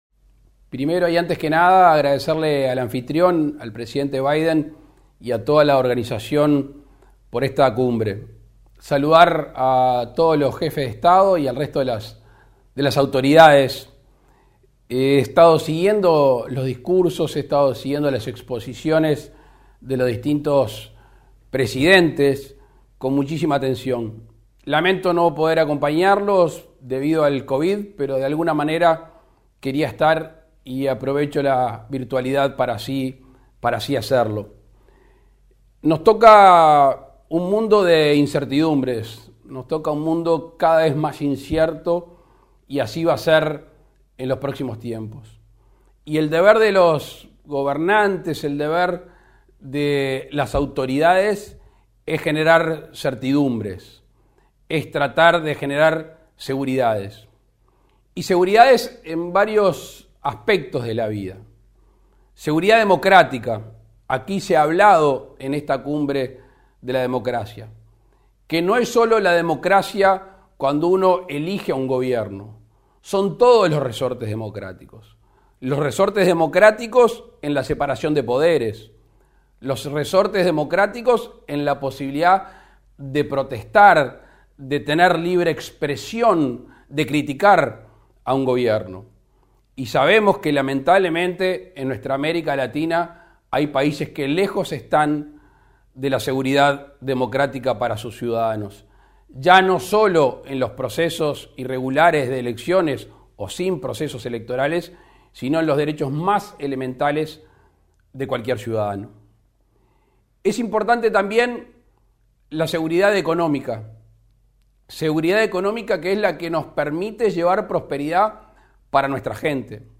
Palabras del presidente de la República, Luis Lacalle Pou, en la IX Cumbre de las Américas
El presidente de la República, Luis Lacalle Pou, participó, mendiante un video, en IX Cumbre de las Américas en Los Ángeles, California, este viernes